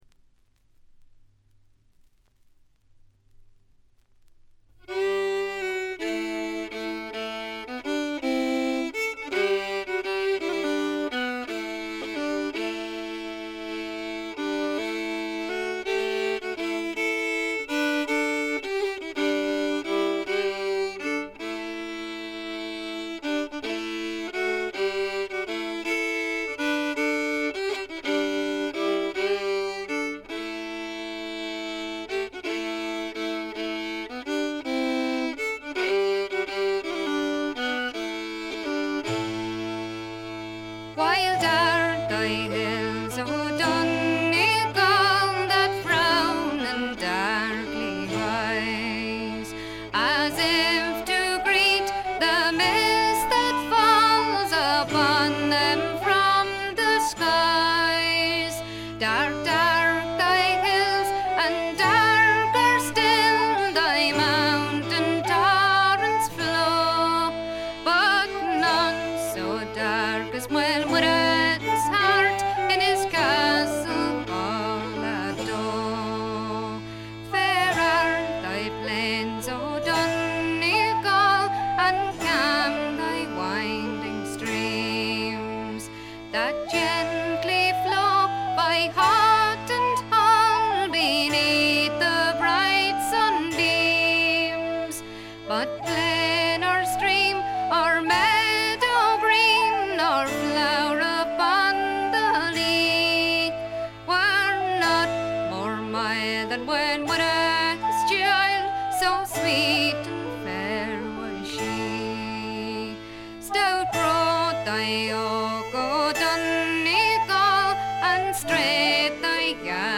ほとんどノイズ感無し。
時に可憐で可愛らしく、時に毅然とした厳しさを見せる表情豊かで味わい深いヴォーカルがまず最高です。
これにパイプやフルートなどがからんでくると、そこはもう寒そうな哀愁漂う別天地。
試聴曲は現品からの取り込み音源です。